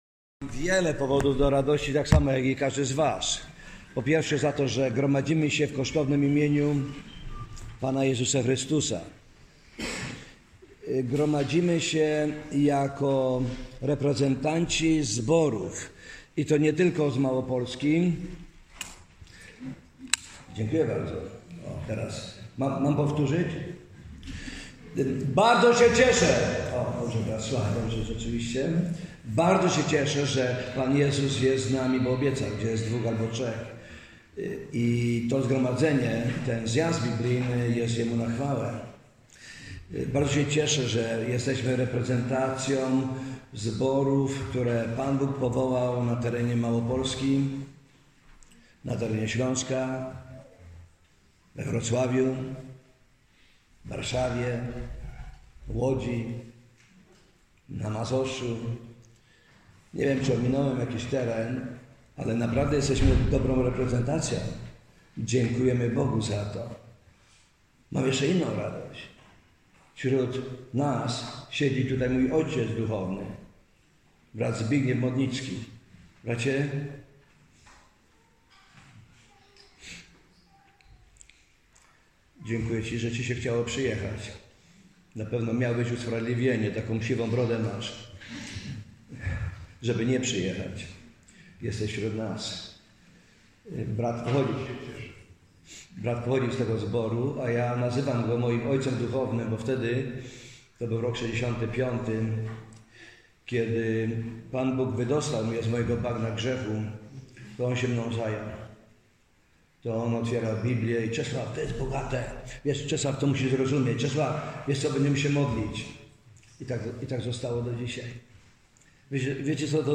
Zjazd ogólny